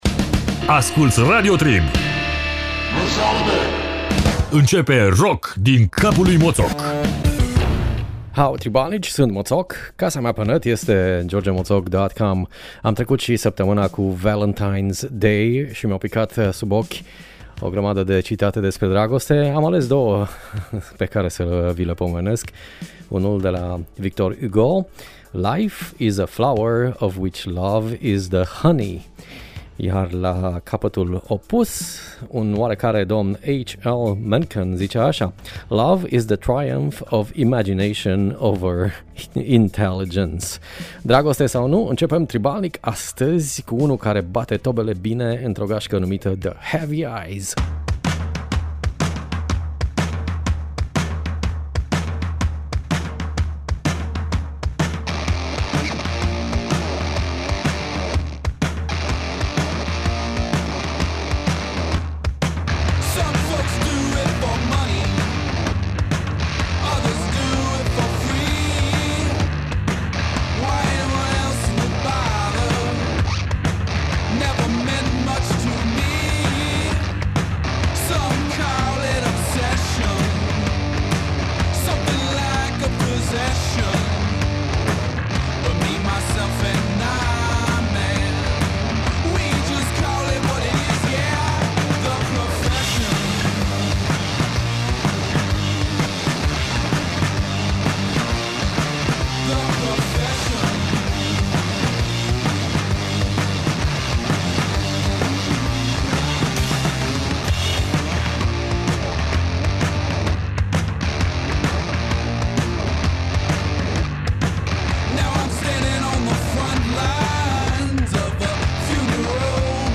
Valentine’s edition: dau ăștia în tobe de le sare dragostea din ele.